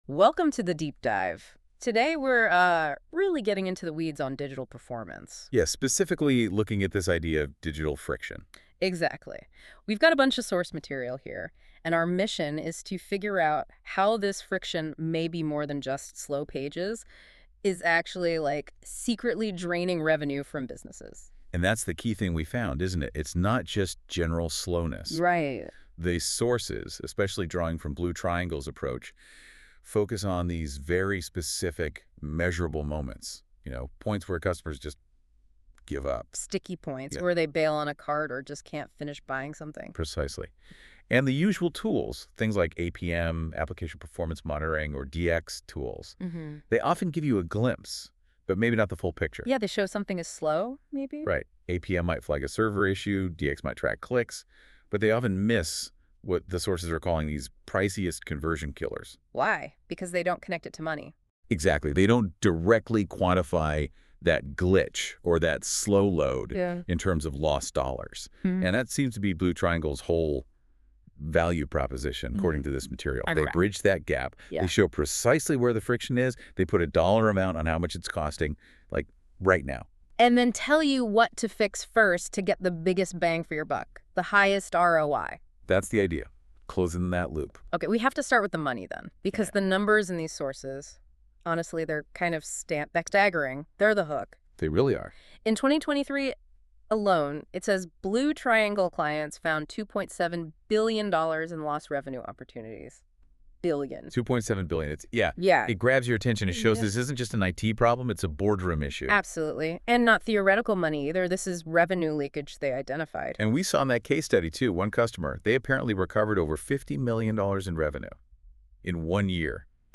The voiceovers and discussions it generated are better than most webinars I've attended.